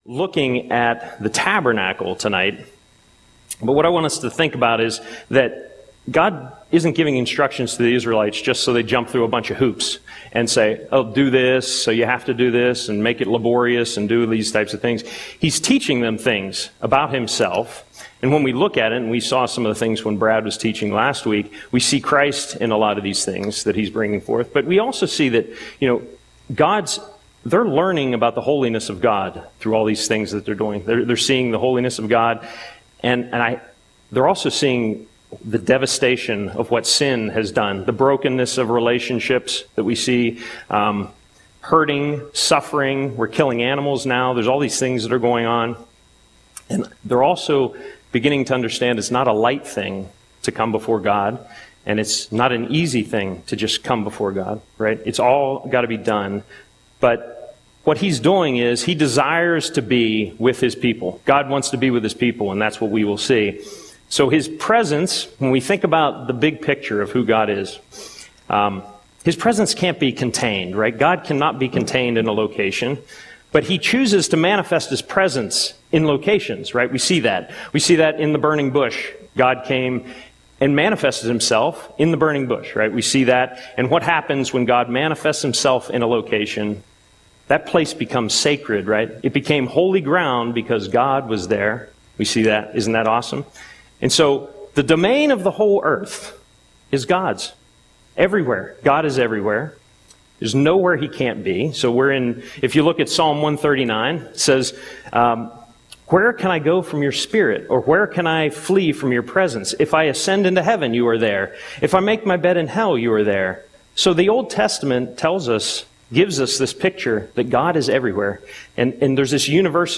Audio Sermon - May 21, 2025